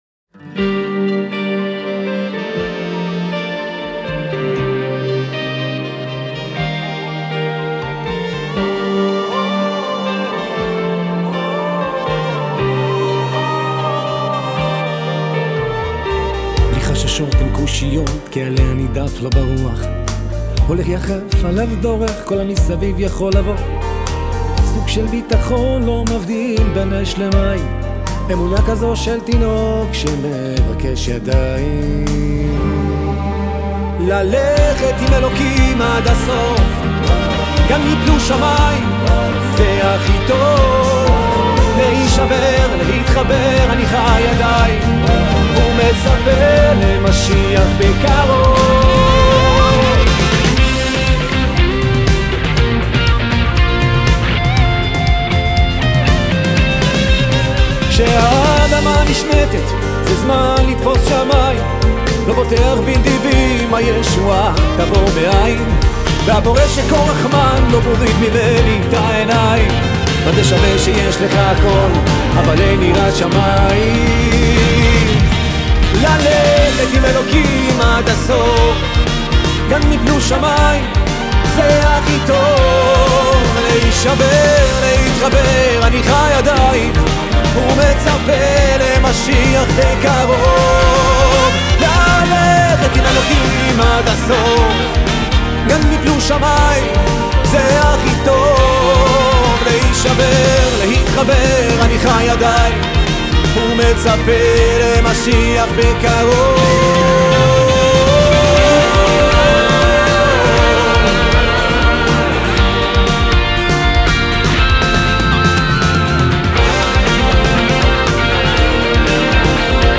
גם אהבתי את השלוב של הגיטרות דיסטורשן
היתה לי תחושה כאילו השיר בטמפו מהיר מידי